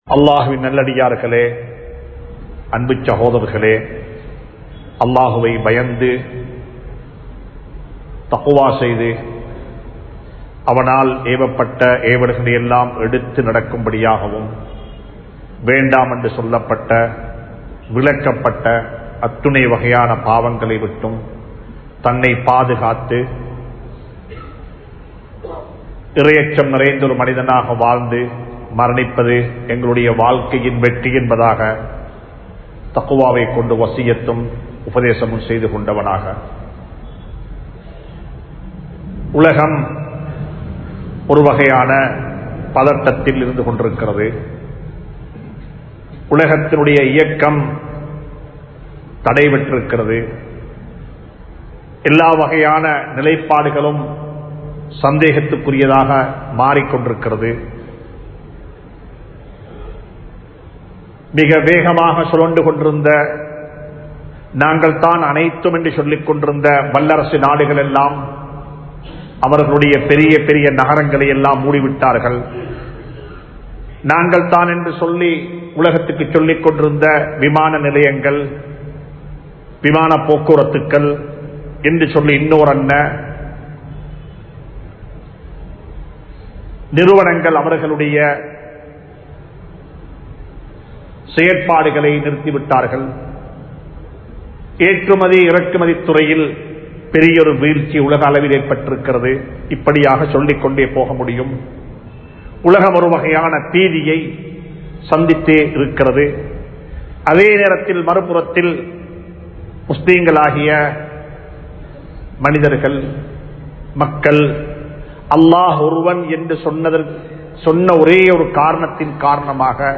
Aatkolli Noaium Islamum (ஆட்கொல்லி நோயும் இஸ்லாமும்) | Audio Bayans | All Ceylon Muslim Youth Community | Addalaichenai
Majma Ul Khairah Jumua Masjith (Nimal Road)